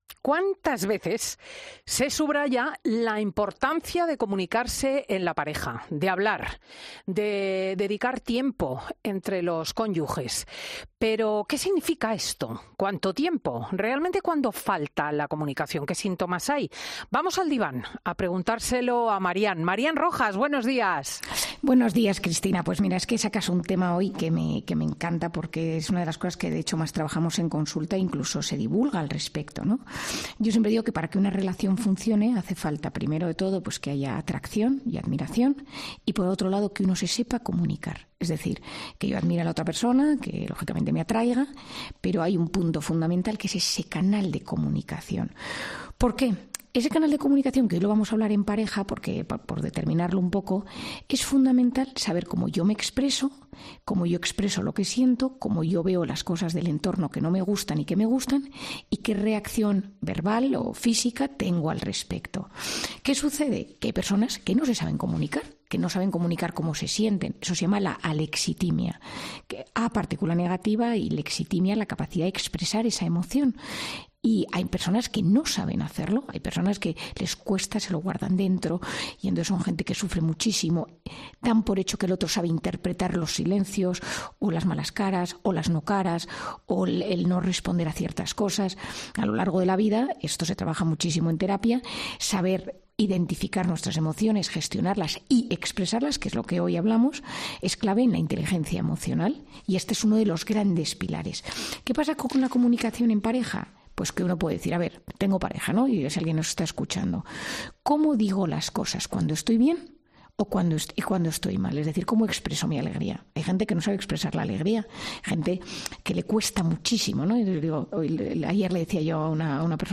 La psiquiatra reflexiona en 'Fin de Semana' sobre la importancia de hablar y comunicarse en pareja